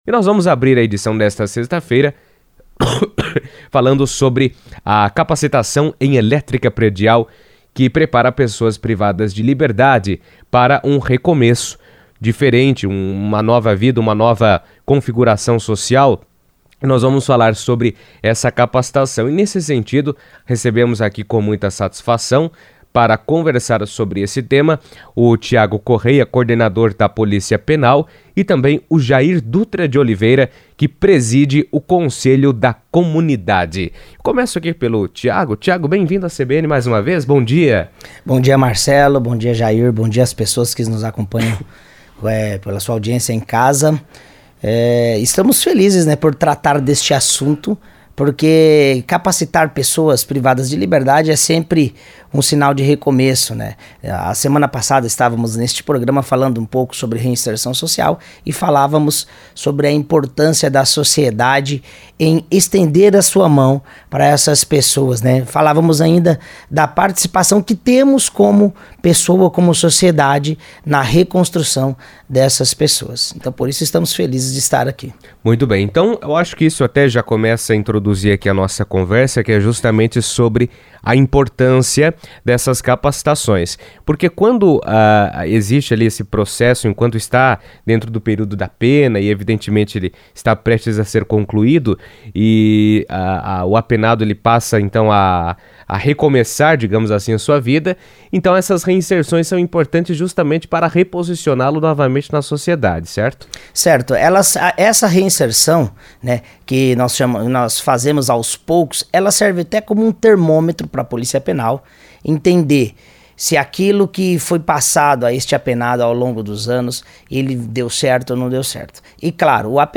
Um programa de capacitação em elétrica predial foi realizado recentemente com presos, oferecendo a oportunidade de adquirir habilidades profissionais e aumentar suas chances de reintegração à sociedade após o cumprimento da pena. Em entrevista à CBN